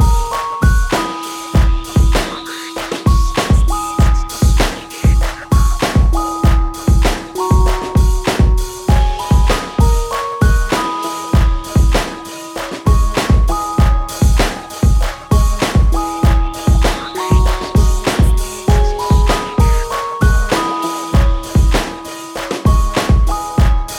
no Backing Vocals R'n'B